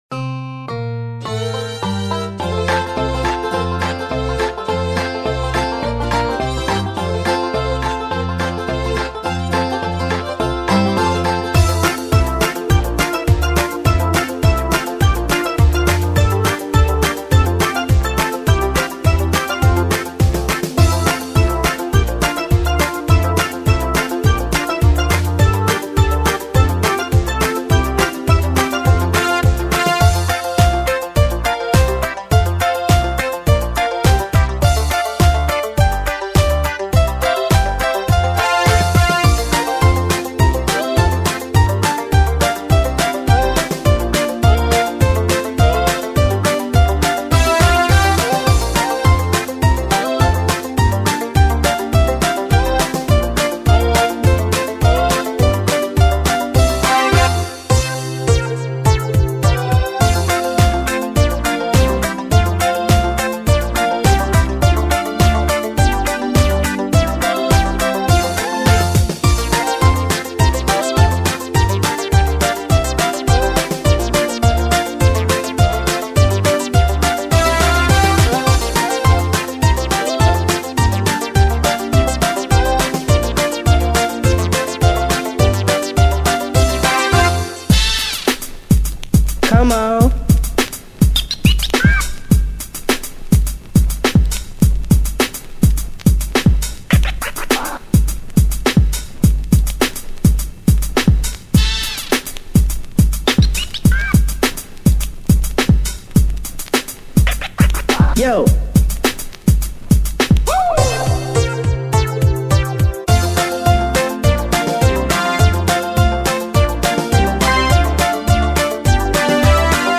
поделитесь у кого есть минусовкой вот этой